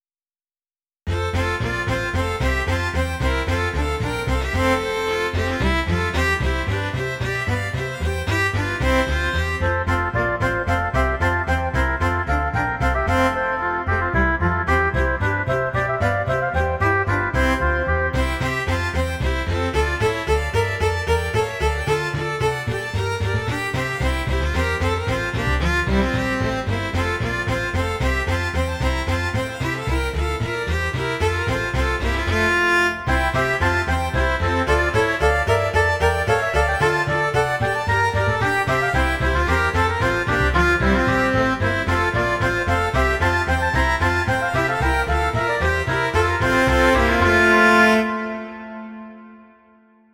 Barroco
danza
sintetizador